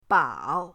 bao3.mp3